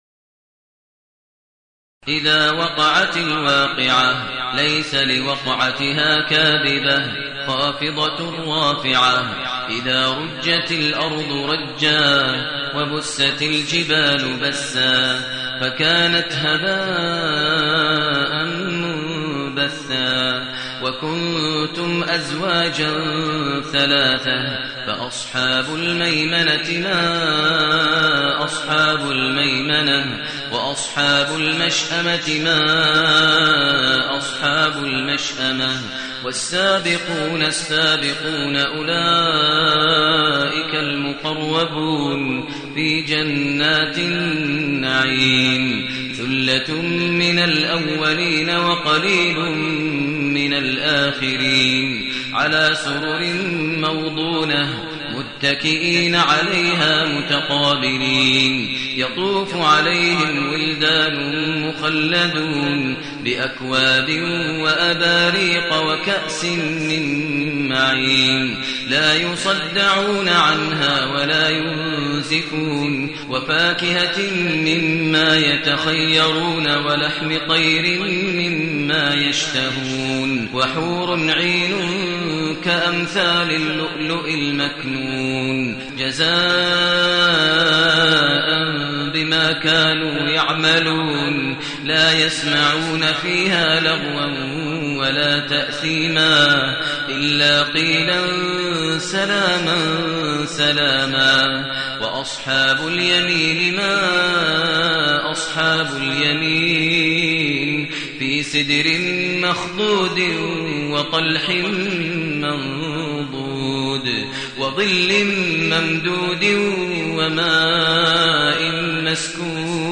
المكان: المسجد النبوي الشيخ: فضيلة الشيخ ماهر المعيقلي فضيلة الشيخ ماهر المعيقلي الواقعة